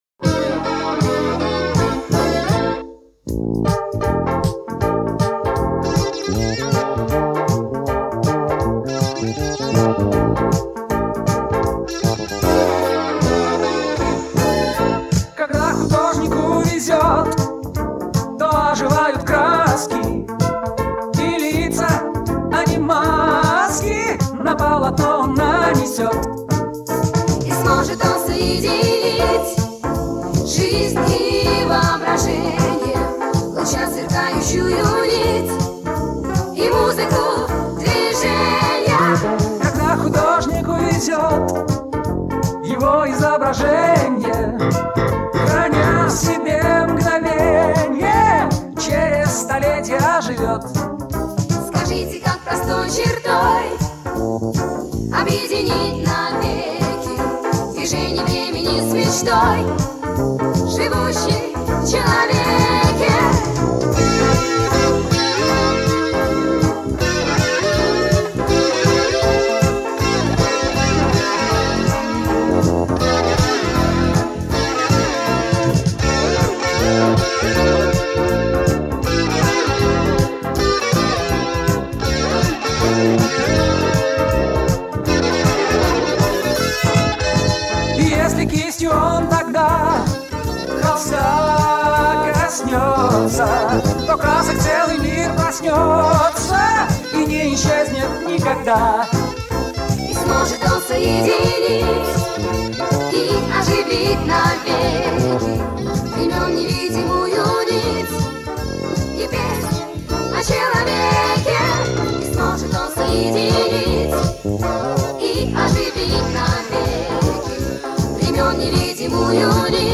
ударные